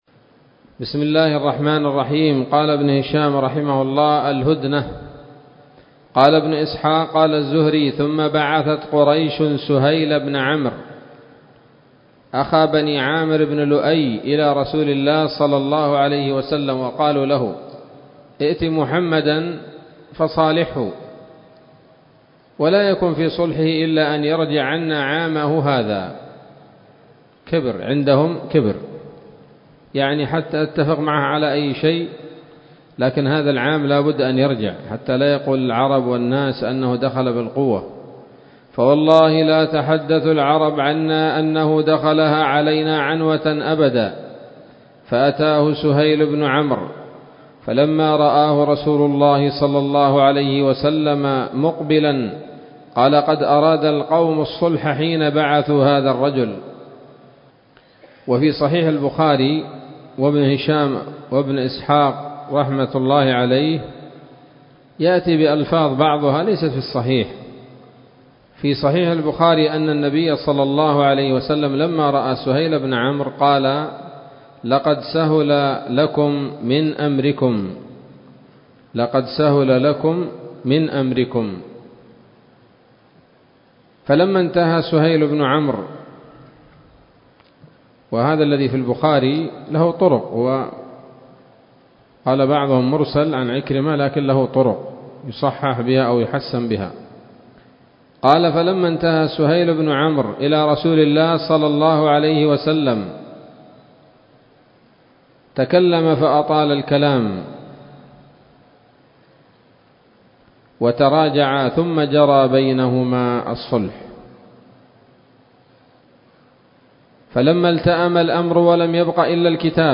الدرس الثالث والثلاثون بعد المائتين من التعليق على كتاب السيرة النبوية لابن هشام